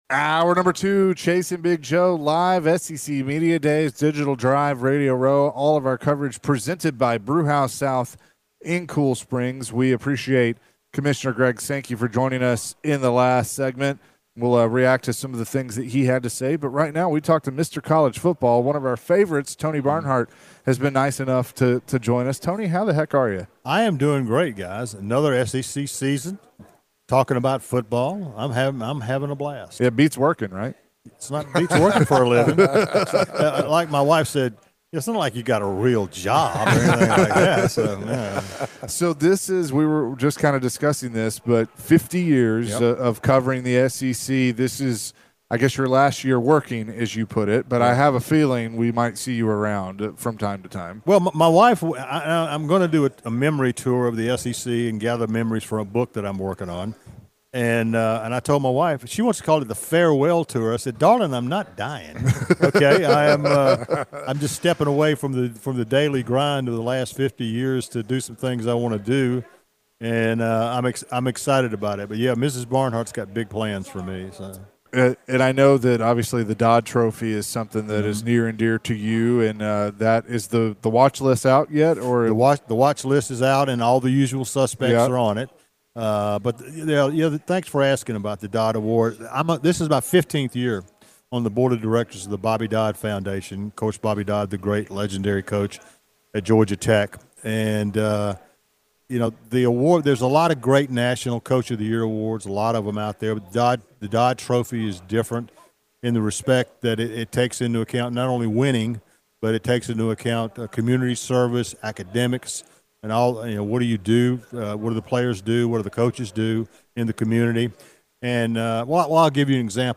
To end the hour, the great David Cutcliffe joined the show and was asked about his new role with the SEC.